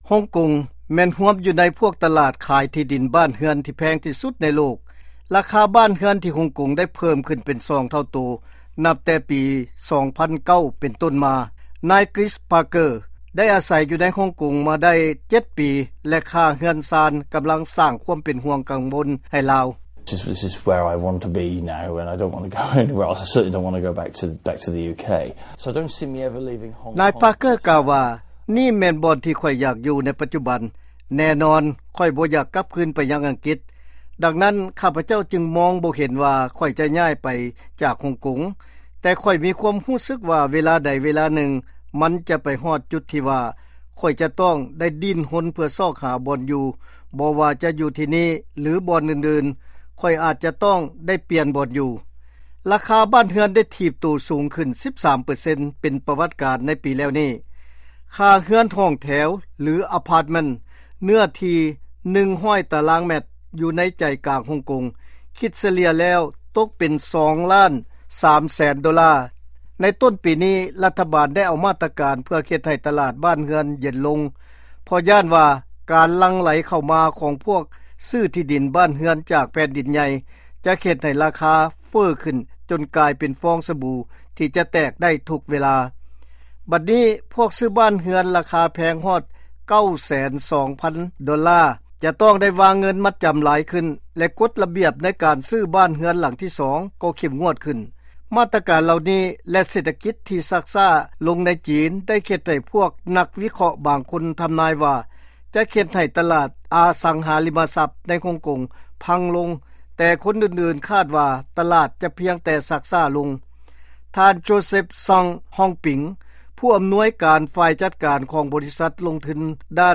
ຟັງລາຍງານເລື້ອງລາຄາບ້ານເຮືອນ ທີ່ຮົງກົງ